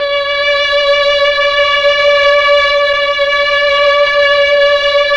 Index of /90_sSampleCDs/Roland LCDP09 Keys of the 60s and 70s 1/STR_Melo.Strings/STR_Tron Strings